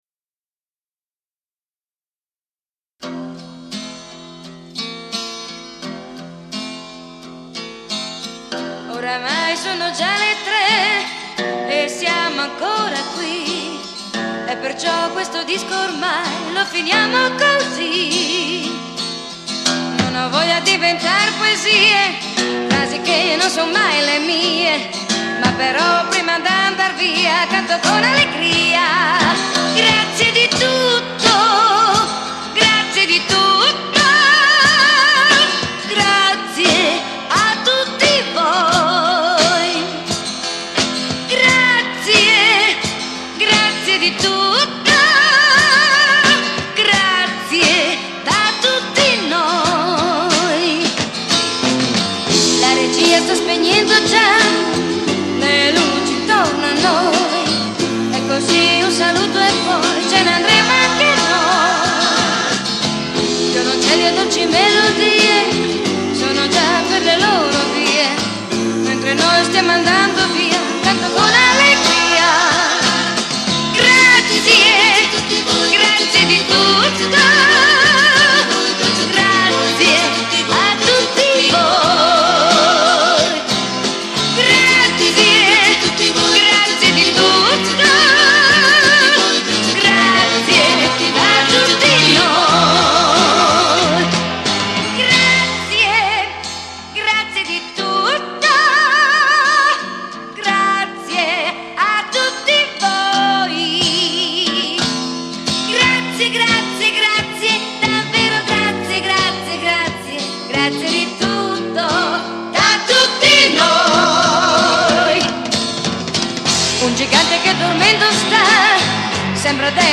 CHITARRA ACUSTICA, ELETTRICA
PERCUSSIONI
BASSO
PIANO, MOOG